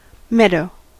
Ääntäminen
US : IPA : /ˈmɛdoʊ/